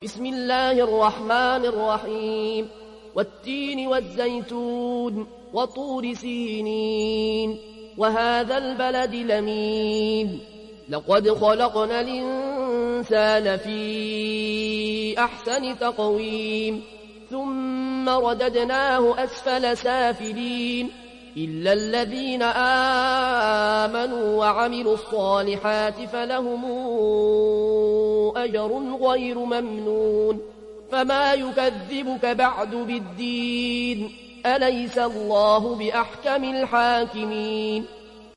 উপন্যাস Warsh থেকে Nafi